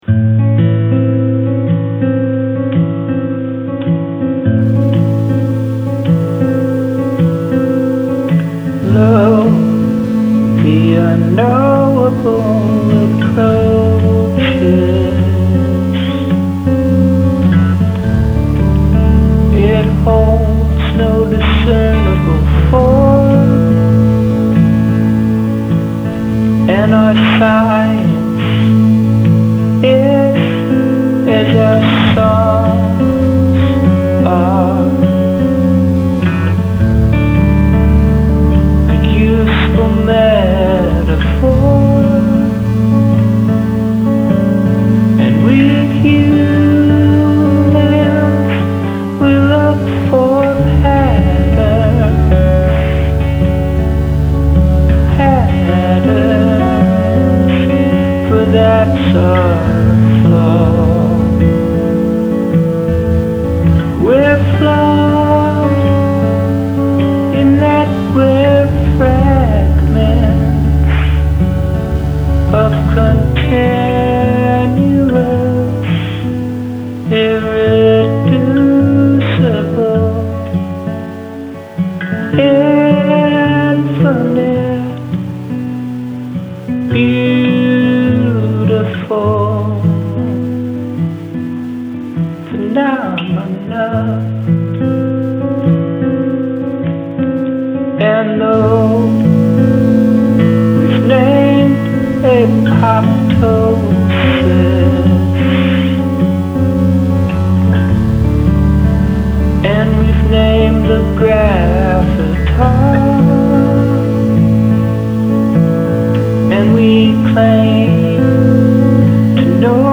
Music
capo on the second fret. not sure i'm naming these chords correctly...
(only room with grounded outlets) so i took the guitar in the living room and recorded it into my cell phone. Then i uploaded the track to the PC and threw a few synths on top of it.
The fidelity is pretty awful, and you can hear tons of digital aliasing.
Love the subtle synths on this one! This is a good combination of the stark acoustic stuff you've done in the past and the electronic stuff you've been working with lately.
The fact that the synths drop out there might be part of it.